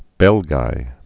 (bĕlgī, -jē)